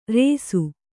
♪ rēsu